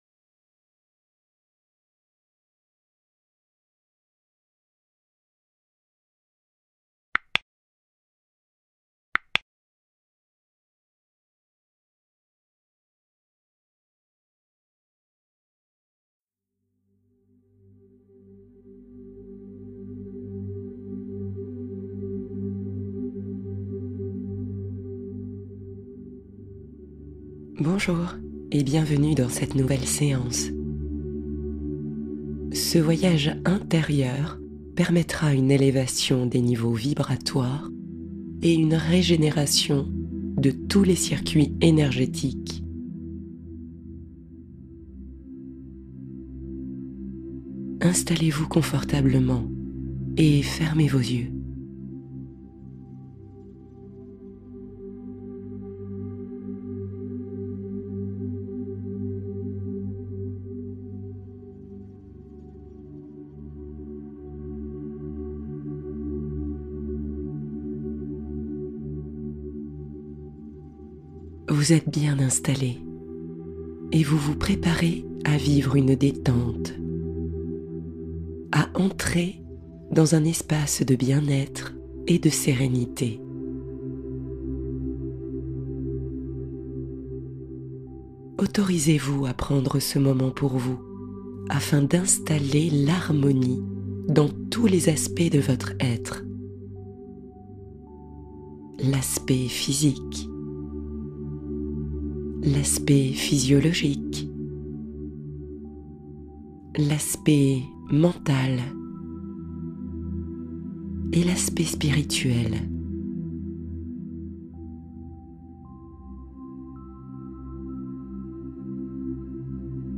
Yoga nidra — Douceur, lenteur et légèreté